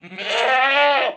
Minecraft Version Minecraft Version latest Latest Release | Latest Snapshot latest / assets / minecraft / sounds / mob / goat / screaming_pre_ram3.ogg Compare With Compare With Latest Release | Latest Snapshot
screaming_pre_ram3.ogg